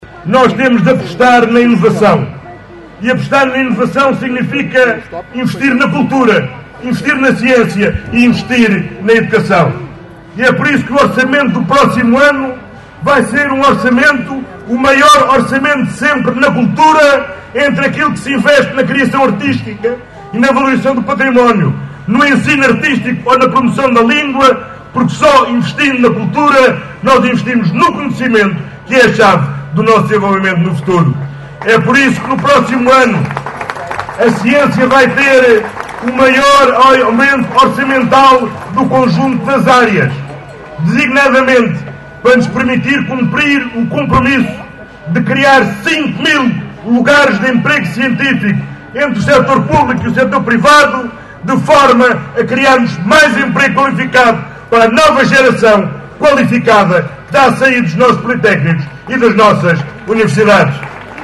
Os socialistas rumaram a norte no passado sábado (25 de agosto) para a habitual “rentrée” política que este ano teve lugar no Parque Municipal em Caminha.